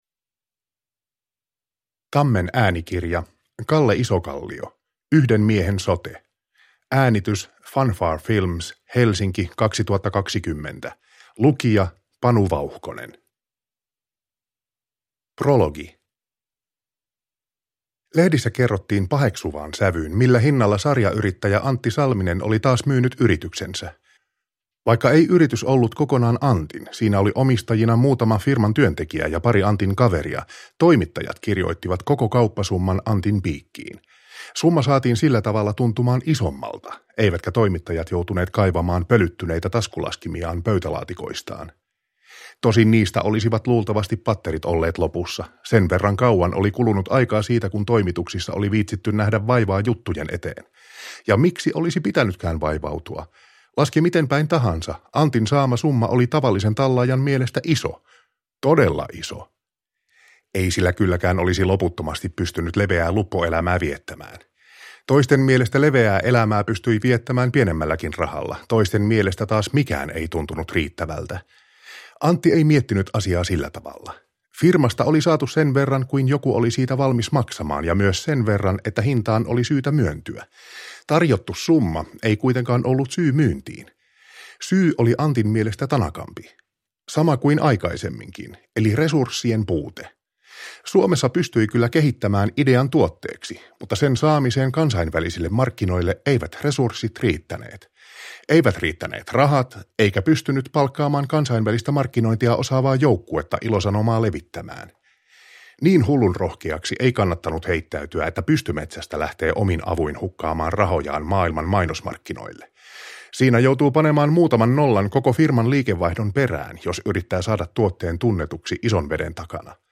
Yhden miehen sote – Ljudbok